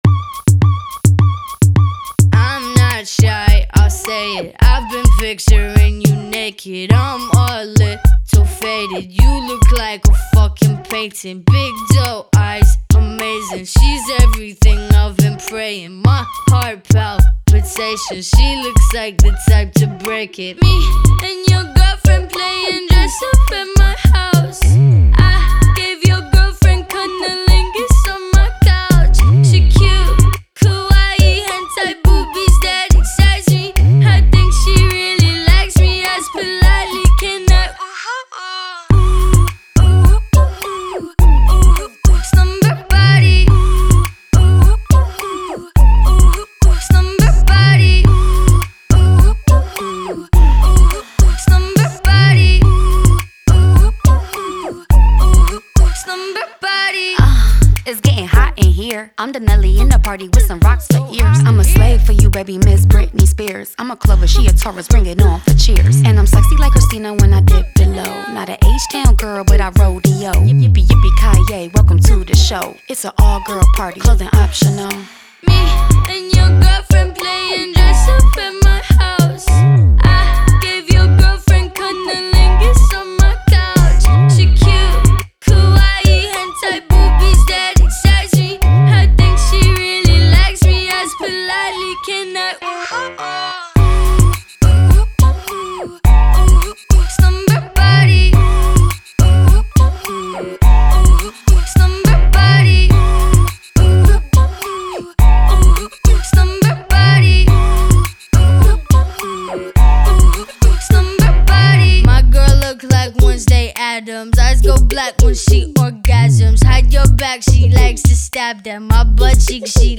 это яркая и энергичная композиция в жанре поп и хип-хоп